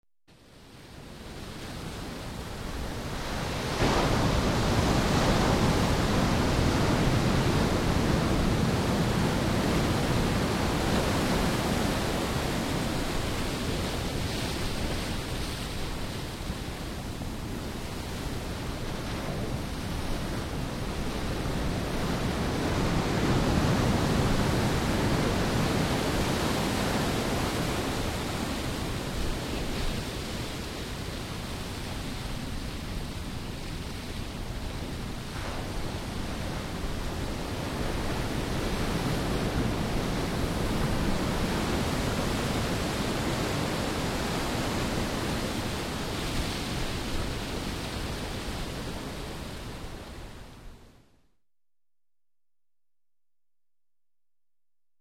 Здесь вы найдете успокаивающие шум волн, грохот прибоя и шелест прибрежного песка.
Грохот морского прибоя с мощными волнами